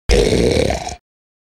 Dp Snort Sound Button - Free Download & Play